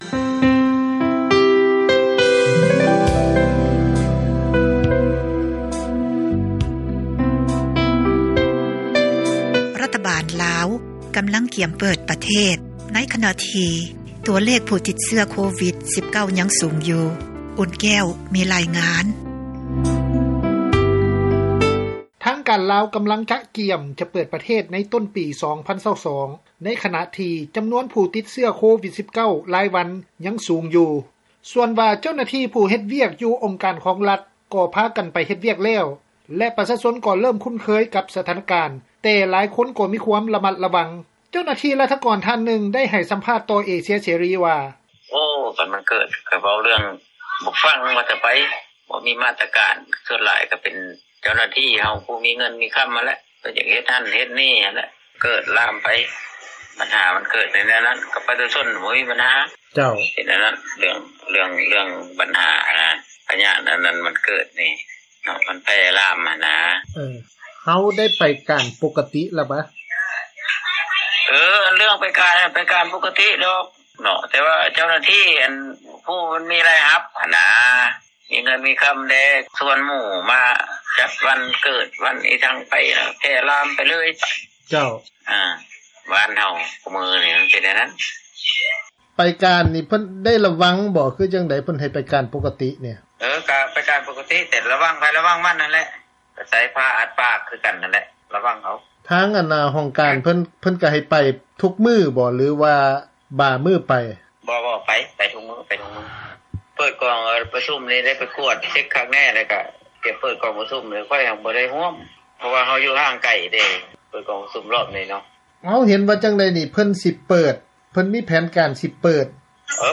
ເຈົ້າໜ້າທີ່ຣັຖກອນທ່ານນຶ່ງ ໄດ້ໃຫ້ສໍາດແຕໍ່ເອເຊັຽເສຣີ ວ່າ: